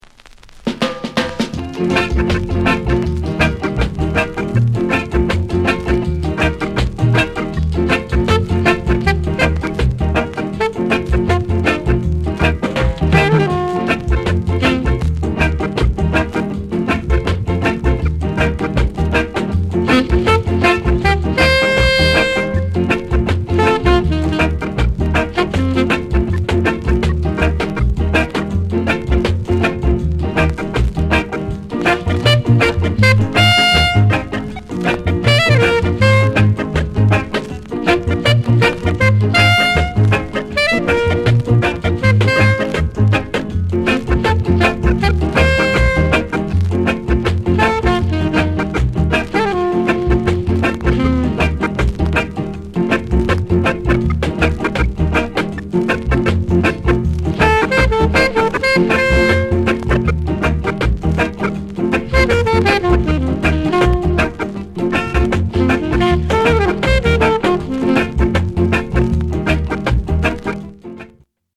SOUND CONDITION A SIDE VG